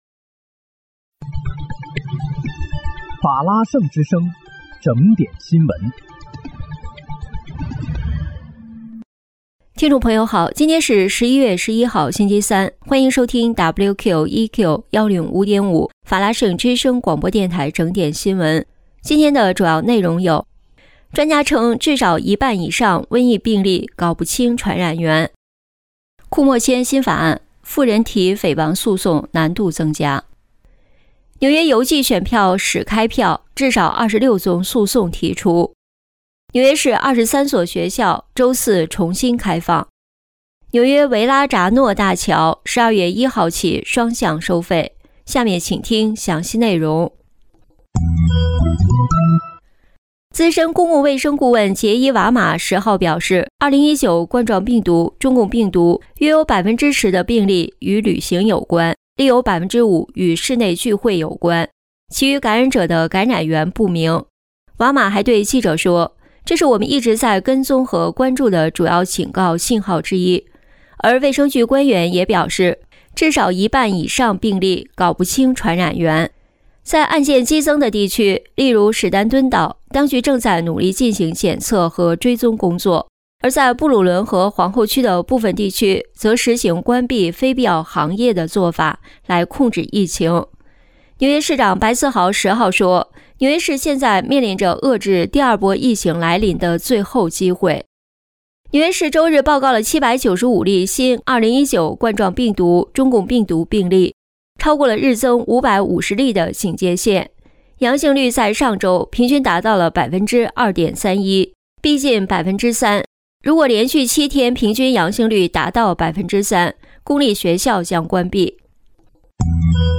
11月11日（星期三）纽约整点新闻